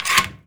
door_lock_slide_04.wav